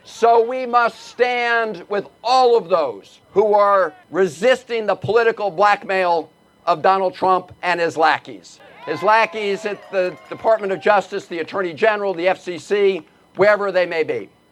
Maryland Senator Chris Van Hollen spoke at the end of the march, saying citizens must stand against attacks on free speech…